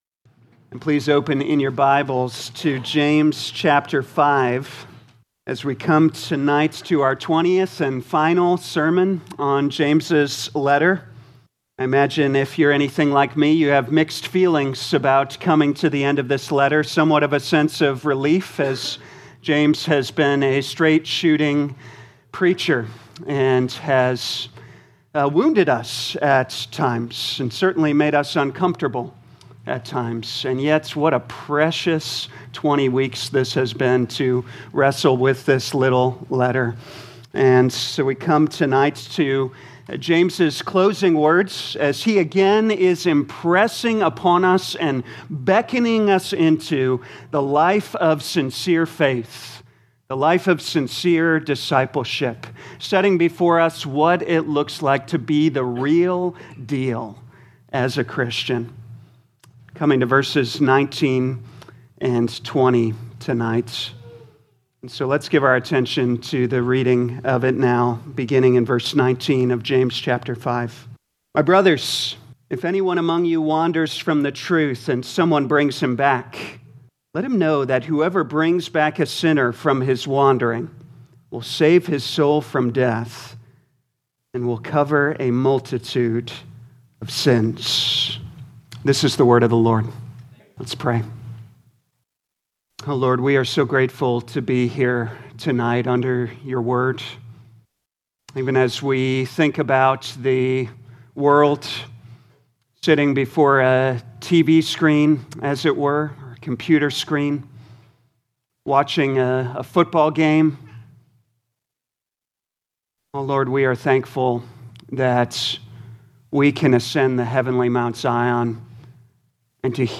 2026 James Evening Service Download